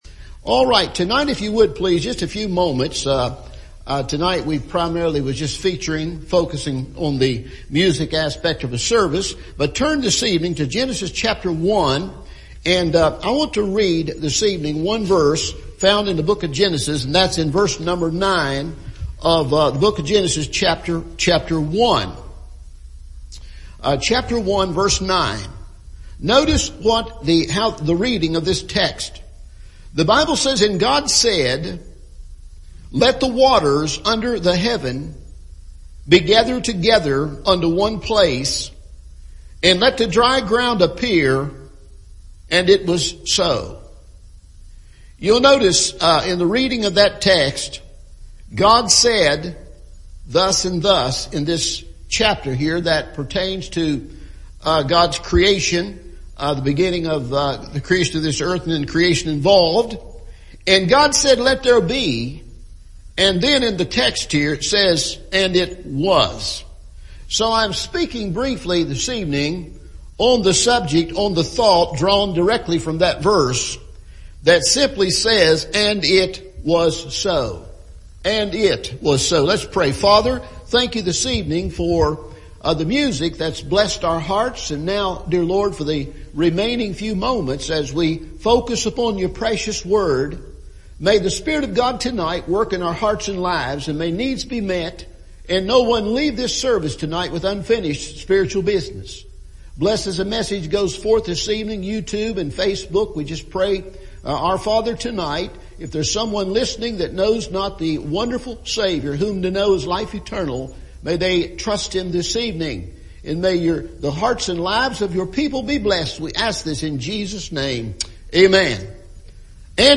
God Said and It Was So – Evening Service